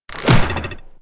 SFX箭射在木板上摇晃抖动声音效下载
SFX音效